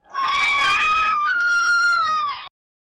キャー！